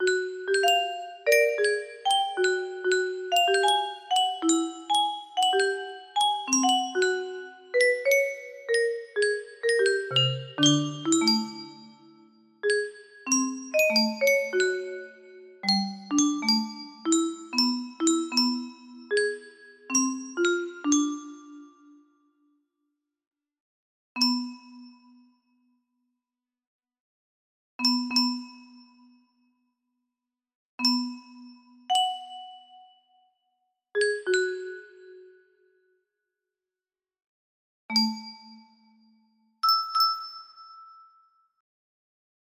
River Tide. music box melody